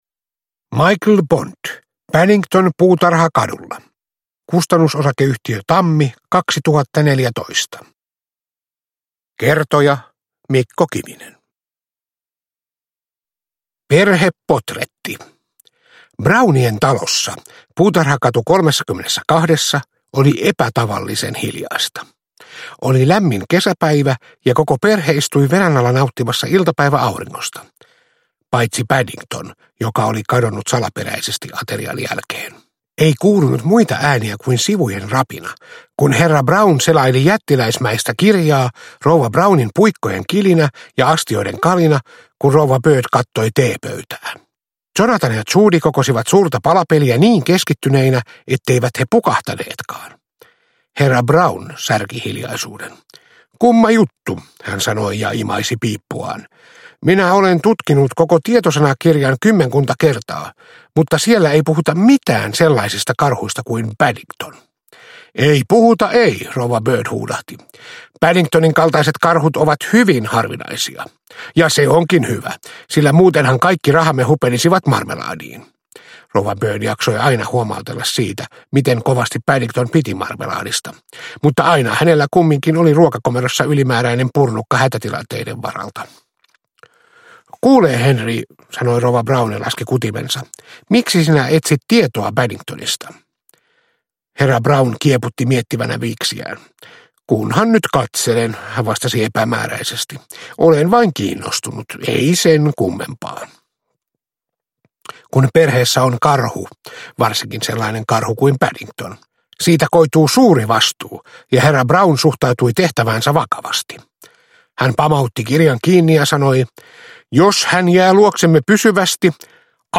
Paddington Puutarhakadulla – Ljudbok – Laddas ner